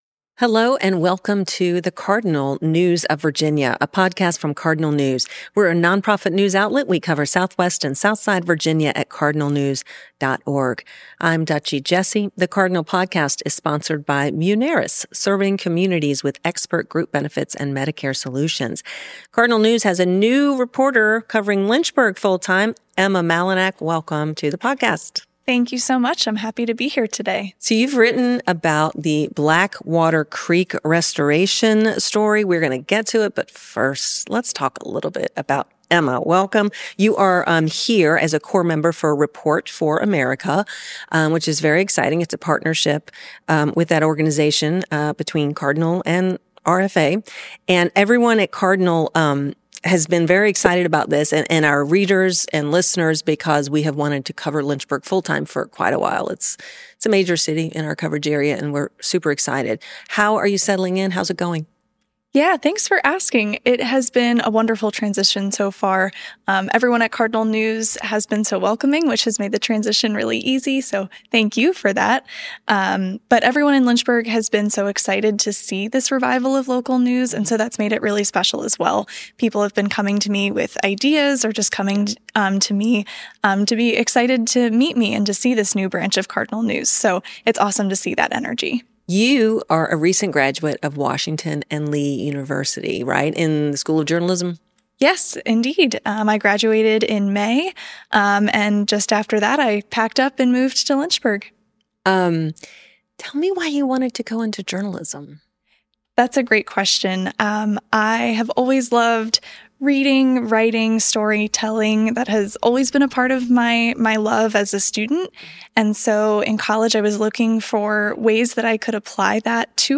It’s a conversation about legacy, landscape, and the tension between human engineering and the natural world, and it’s one you won’t want to miss.